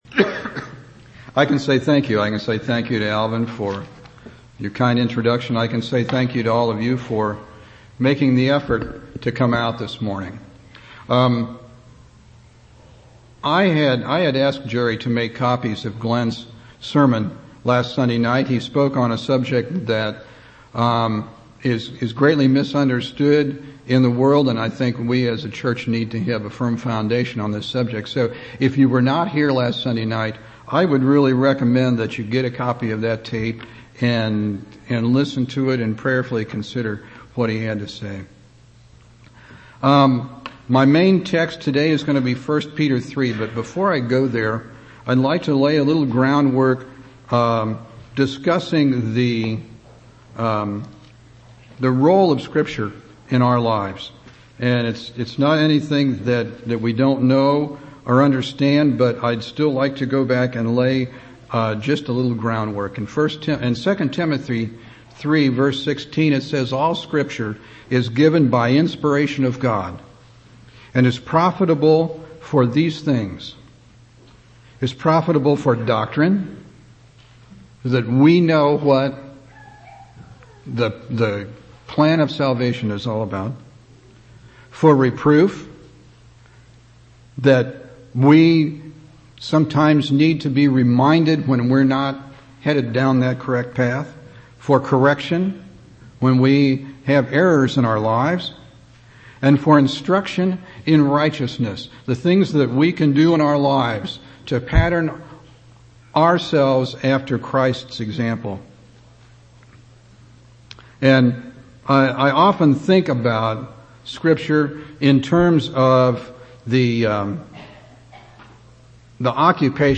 1/18/2004 Location: Temple Lot Local Event